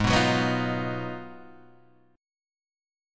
G# Minor 13th